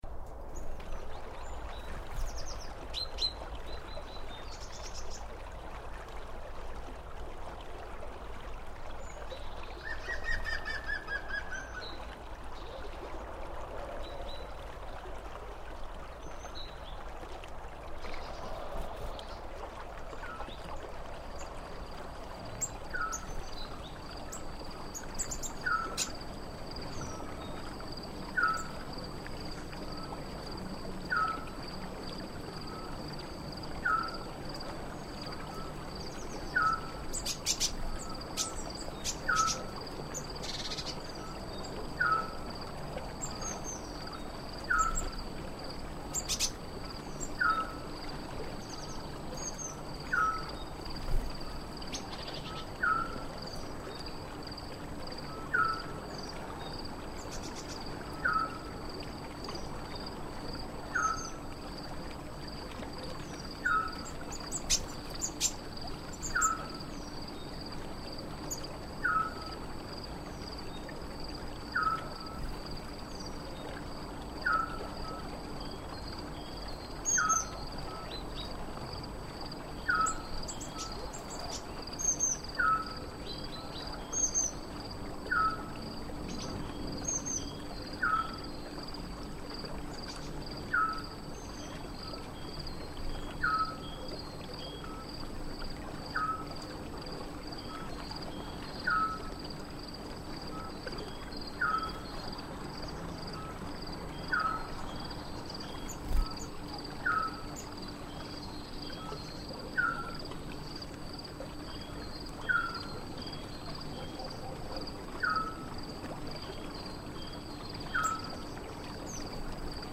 (Nova ventá) Sons da fraga (Nova ventá) Vídeo do xogo 5: Como fai o animaliño?
a sons fraga mesturados.mp3